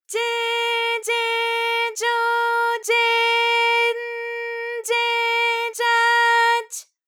ALYS-DB-001-JPN - First Japanese UTAU vocal library of ALYS.